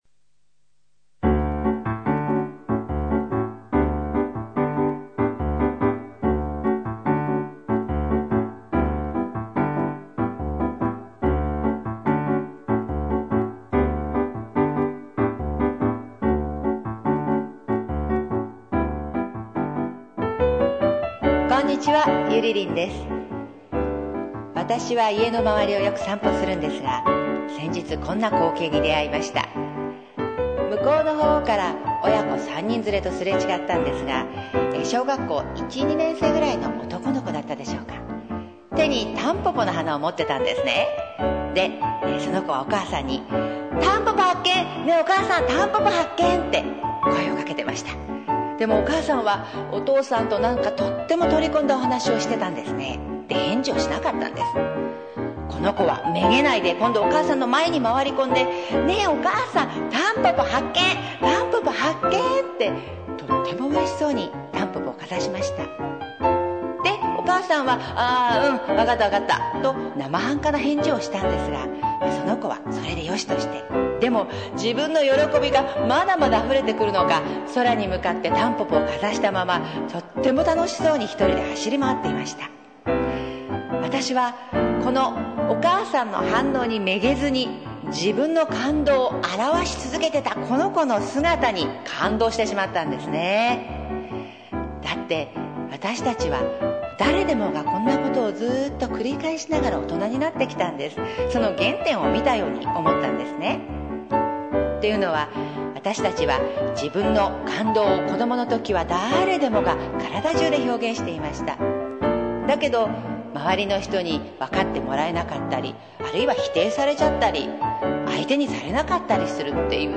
３分間フリートークです。